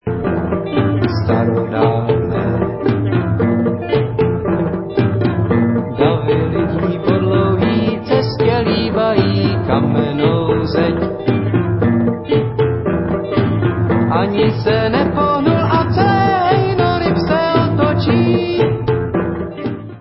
Nahrávalo se ve Zlíně